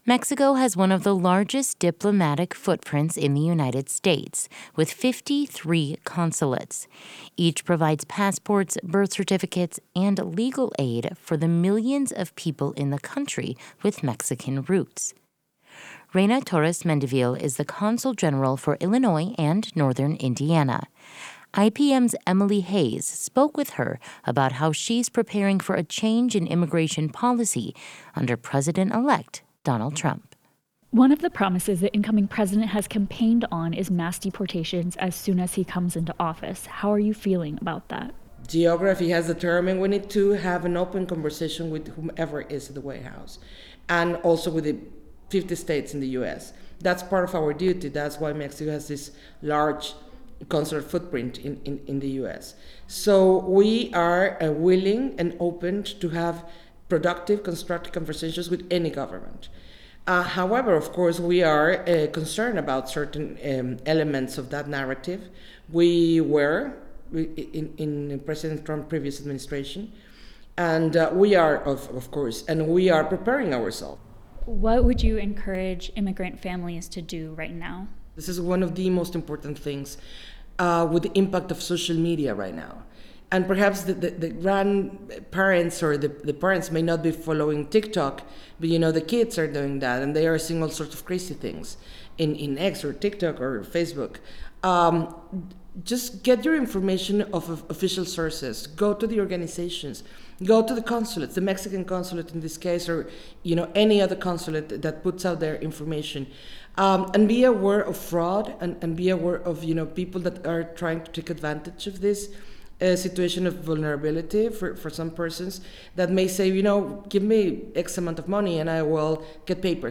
This conversation has been edited for clarity and conciseness.